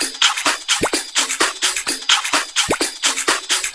VEH1 Fx Loops 128 BPM
VEH1 FX Loop - 01.wav